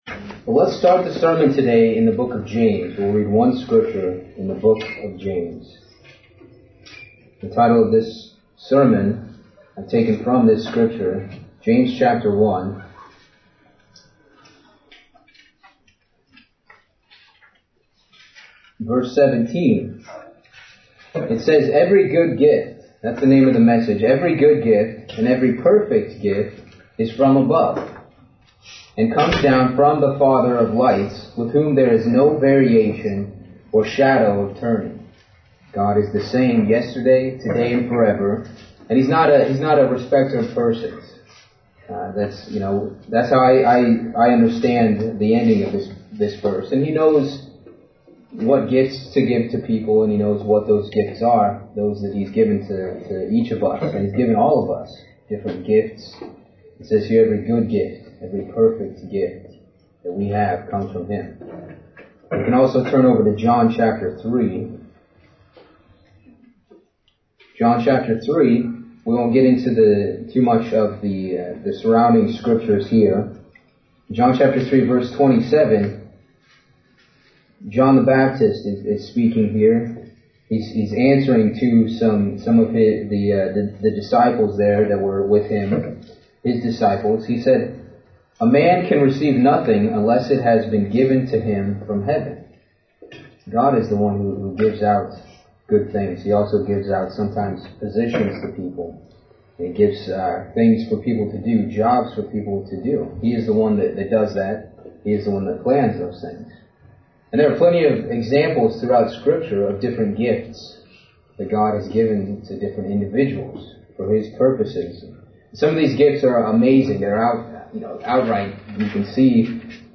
Sermons
Given in New Jersey - North New York City, NY